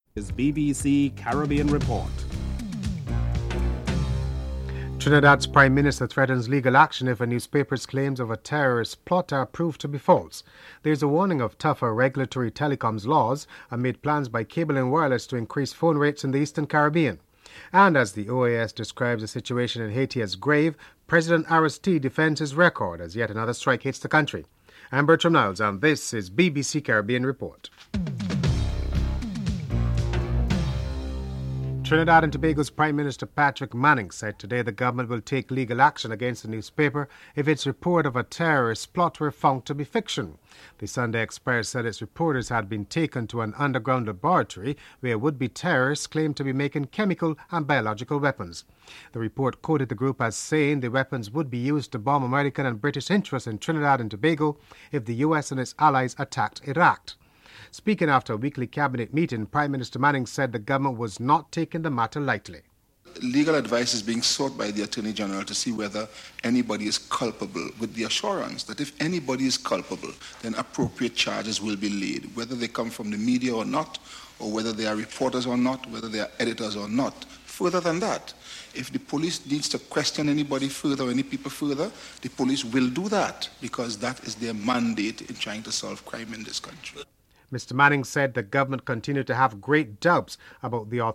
Headlines with anchor